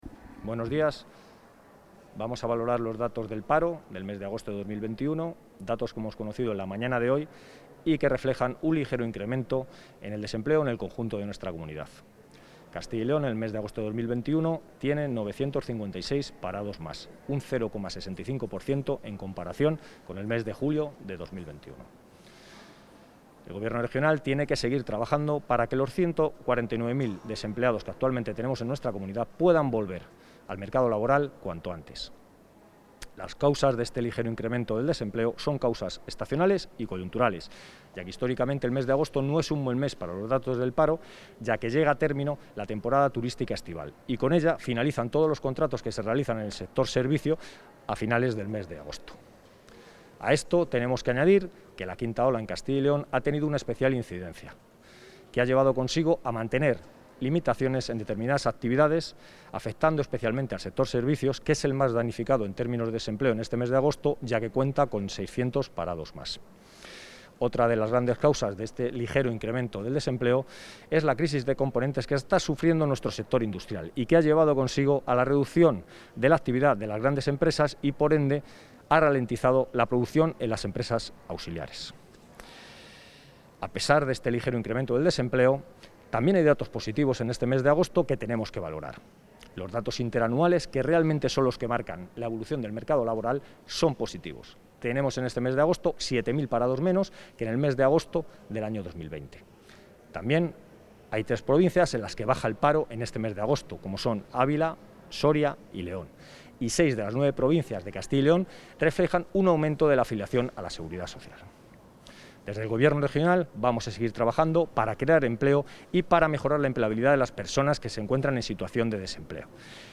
Valoración del viceconsejero de Empleo y Diálogo Social.